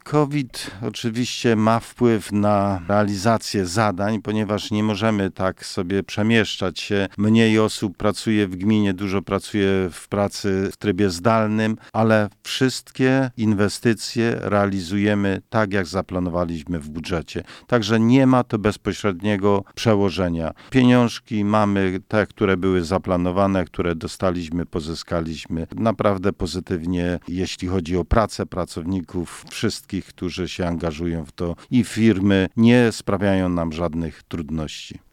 Jak tłumaczy wójt gminy Mielec Józef Piątek, placówka mimo ograniczeń wynikających z obostrzeń, realizuje na bieżąco swoje działania.